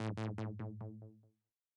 Ray Gunnn.wav